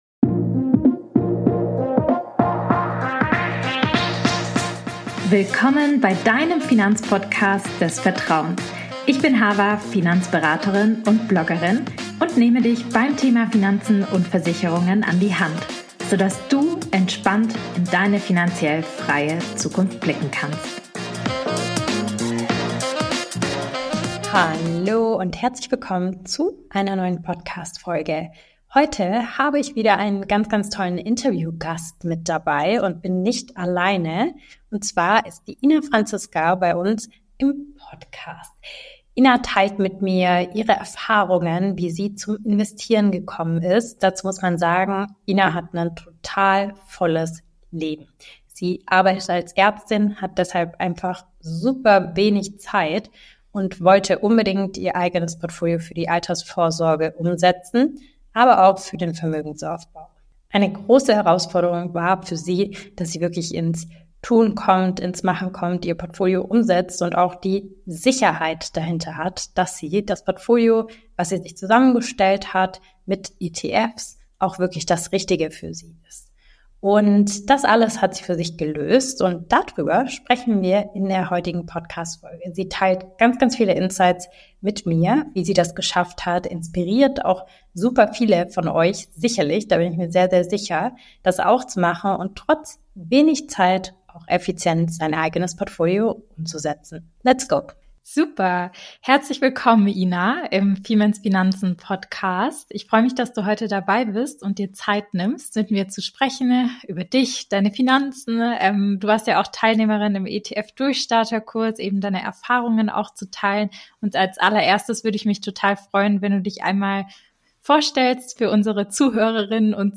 Sie erzählt im Interview, wie sie es geschafft hat ihr eigenes Portfolio erfolgreich umzusetzen.